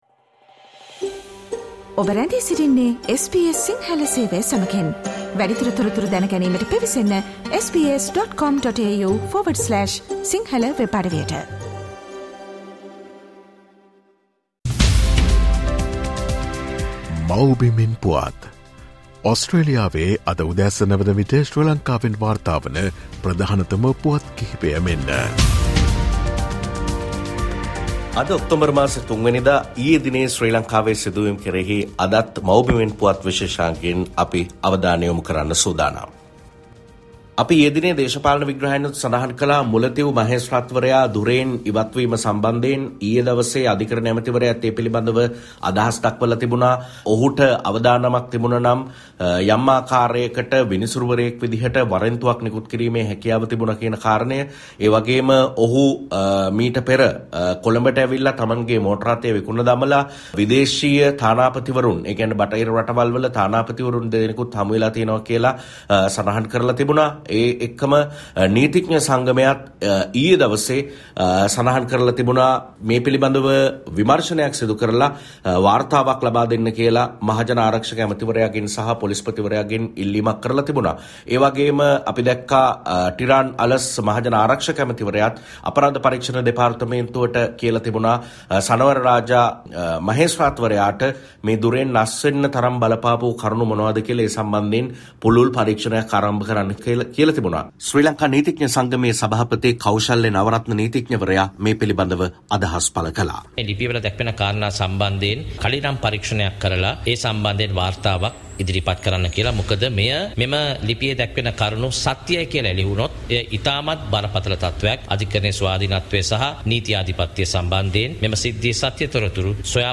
SBS Sinhala radio brings you the most prominent political news highlights of Sri Lanka in this featured Radio update on every Monday.